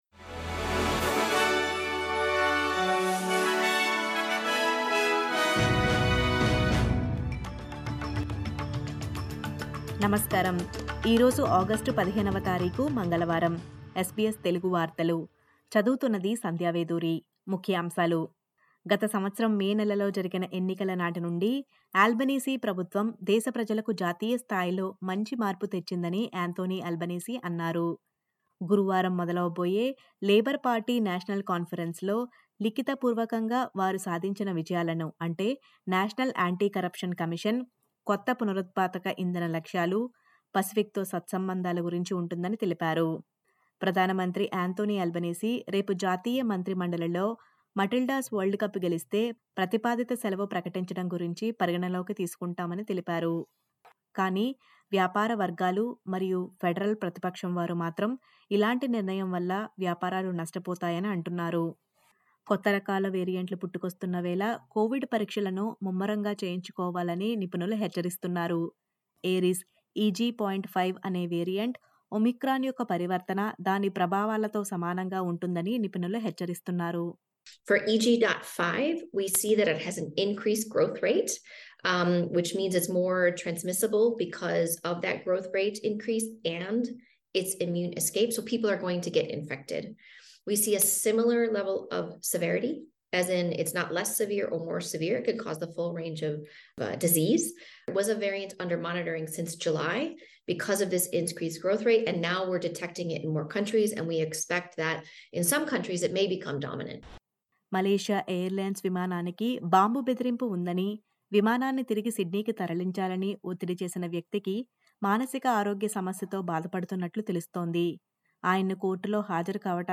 SBS Telugu వార్తలు